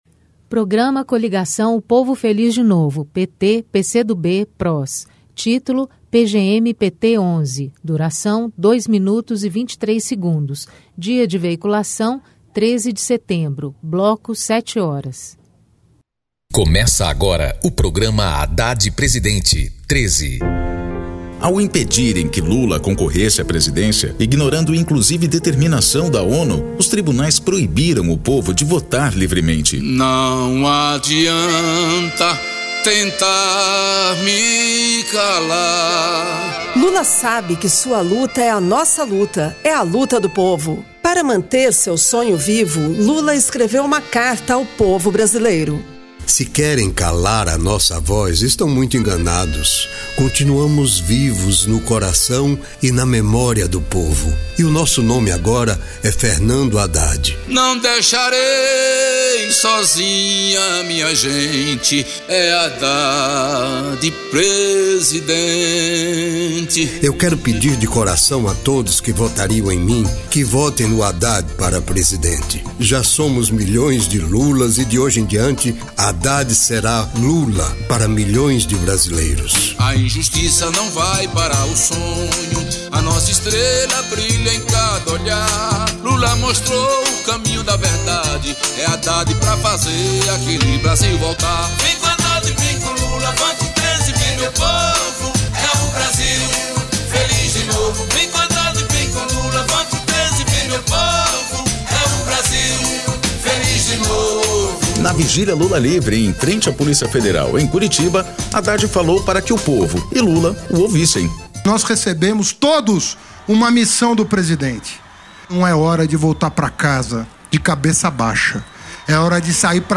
Gênero documentaldocumento sonoro
Descrição Programa de rádio da campanha de 2018 (edição 11) - 1° turno